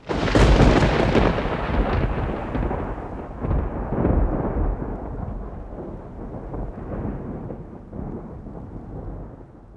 thunder2.wav